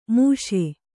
♪ mūśe